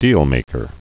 (dēlmākər)